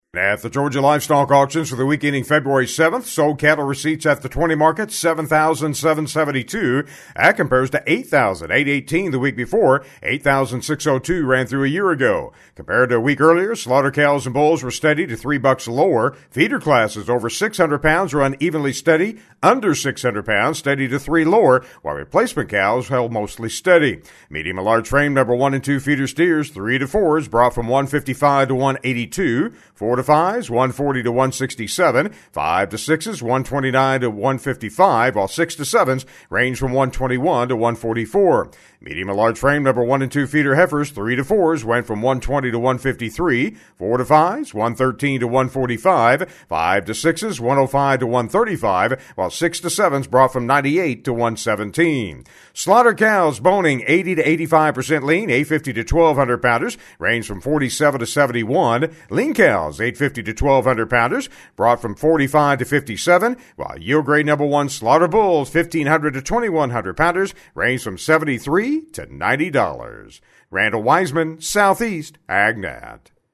GA Weekly Livestock Market Report